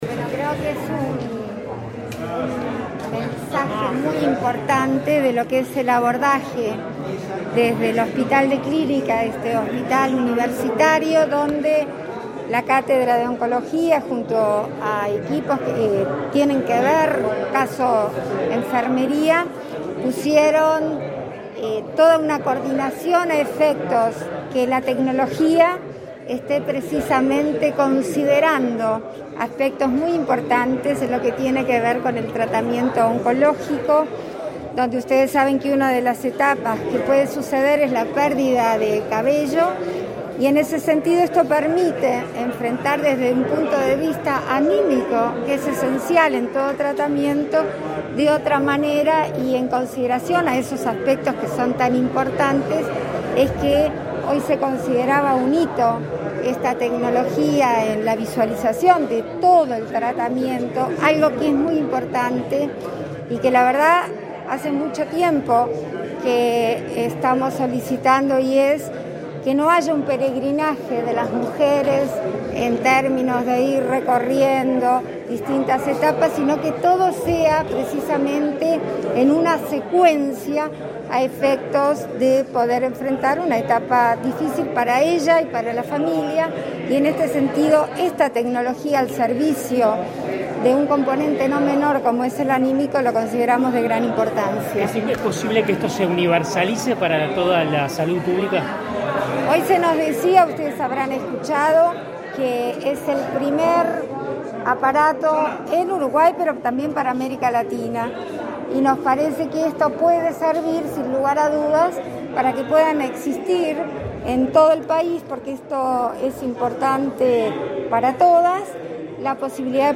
Declaraciones a la prensa de la vicepresidenta, Beatriz Argimón
La vicepresidenta de la República, Beatriz Argimón, dialogó con la prensa, luego de participar de la inauguración de Dignicap, el primer equipo de